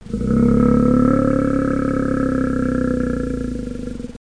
bear-groan-2.mp3